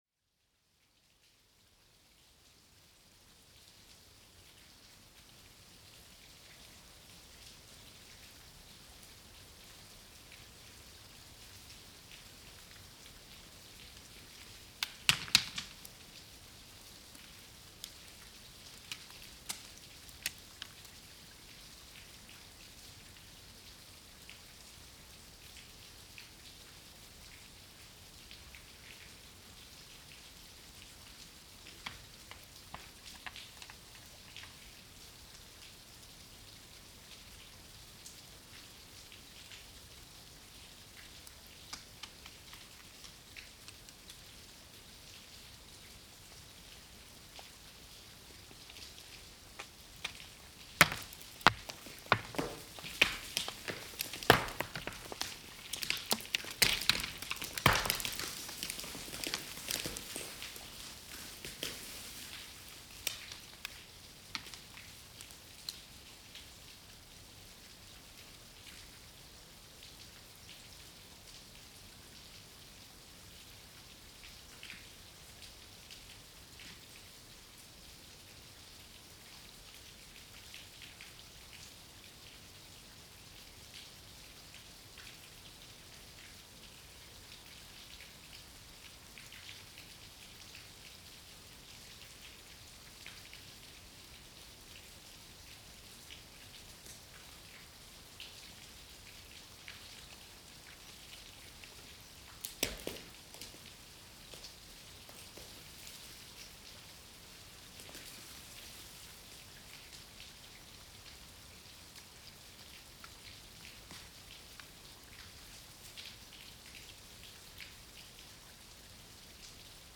The ice cave beneath Útgönguháls in Suðursveit.
Falling rocks
Melting ice
Equipment is placed in a suitable location where the wind was minimal and left there for 30-40 minutes while taking a walk.
This ice cave was at the end of Breiðamerkurjökull icefall, in a place where the glacier was probably 300 meters thicker only 40 years ago when I first came to this part of Iceland.